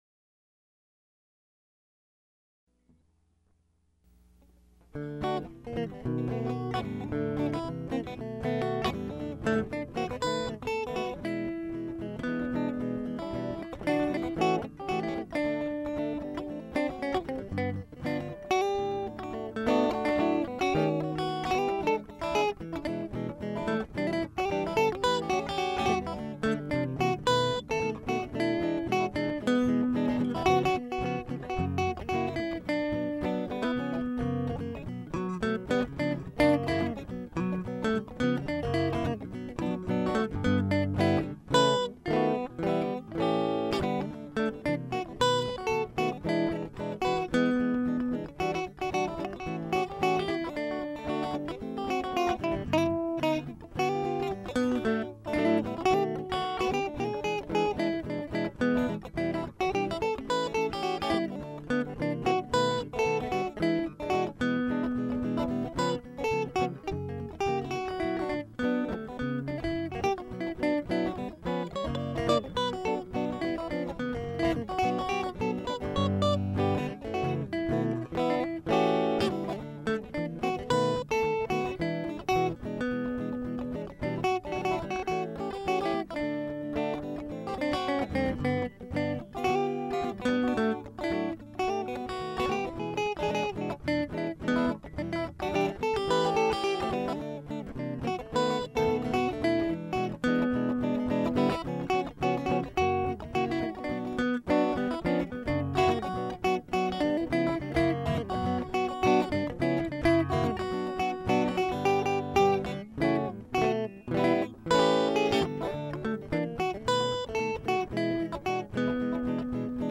Pratique à deux guitares
Pratique d’un vieux standard US
indiana-2-guit3.mp3